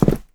jumpland4.wav